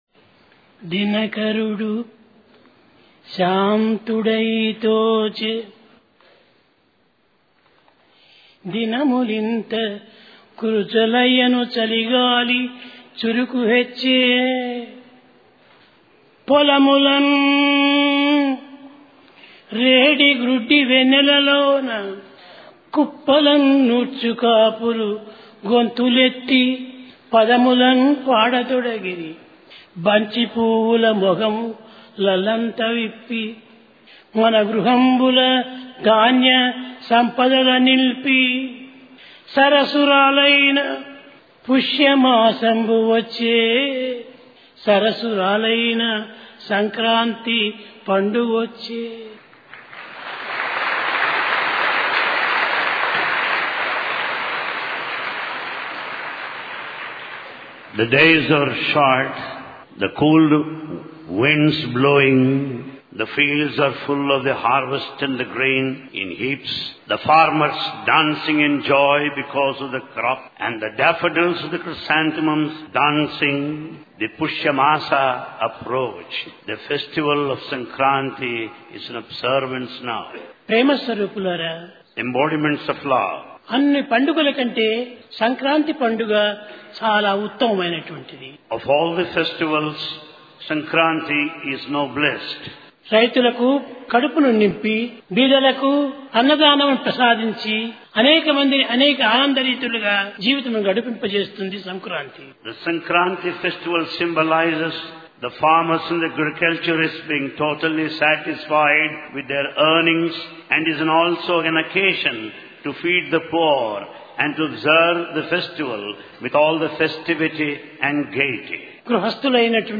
Baba's Divine Discourse, Sankranti 2004: Respect for Parents - the Greatest Virtue
Sai Darshan Home Divine Discourse by Bhagavan Sri Sathya Sai Baba Date: 12 January 2004 Occasion: Sankrant i Place: Prasanthi Nilayam Respect for Parents - the Greatest Virtue The sun shines serene and gentle.